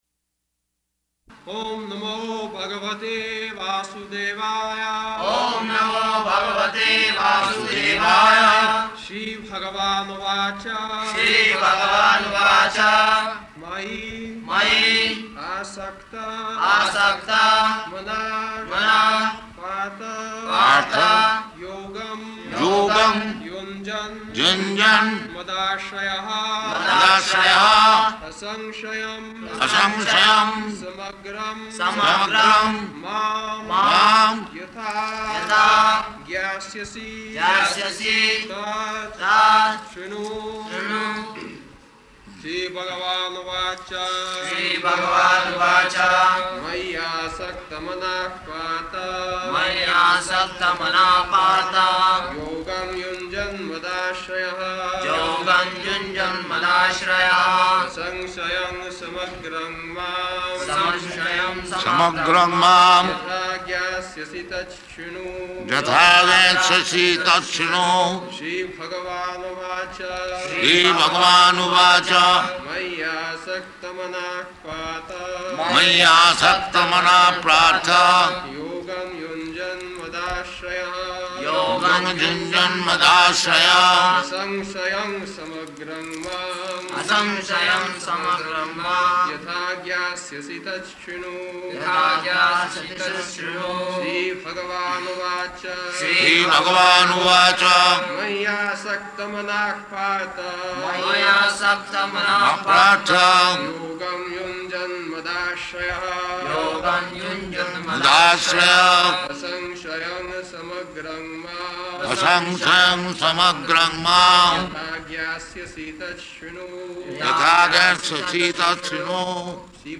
April 27th 1974 Location: Hyderabad Audio file
[Prabhupāda and devotees repeat] [leads chanting of verse]